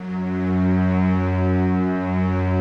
Index of /90_sSampleCDs/Optical Media International - Sonic Images Library/SI1_Swell String/SI1_Slow Swell
SI1 SWELL05R.wav